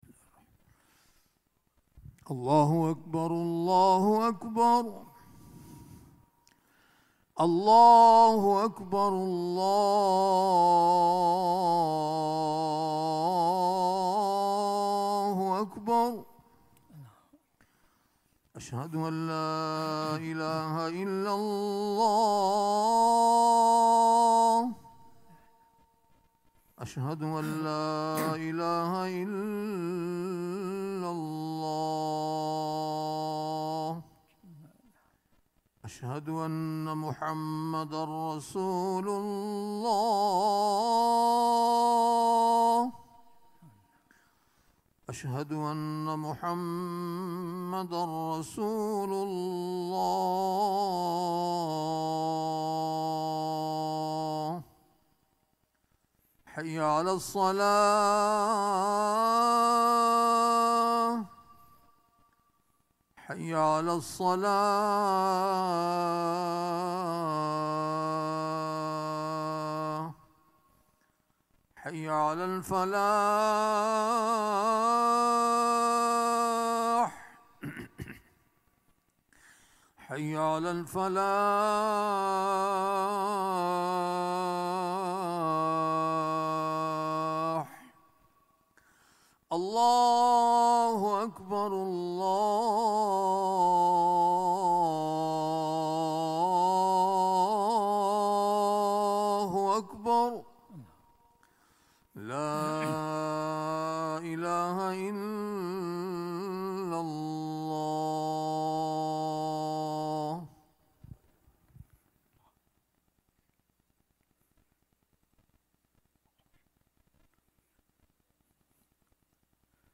Friday Khutbah - "Action in Islam"